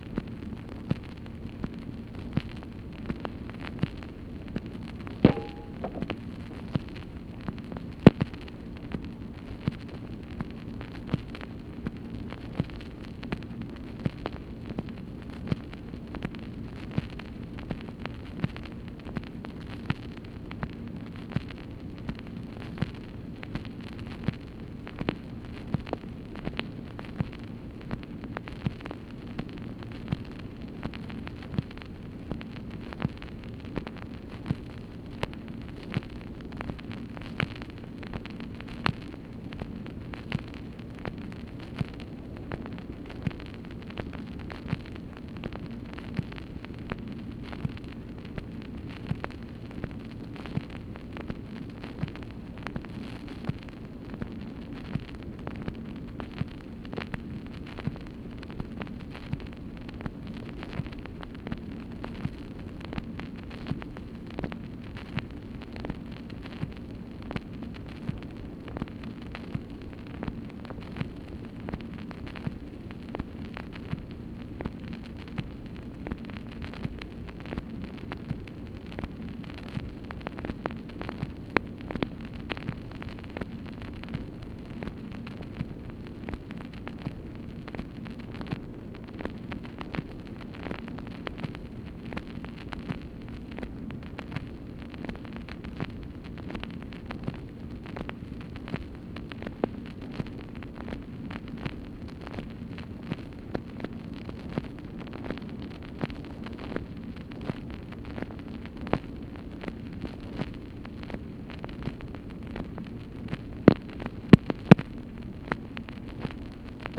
MACHINE NOISE, March 7, 1964
Secret White House Tapes | Lyndon B. Johnson Presidency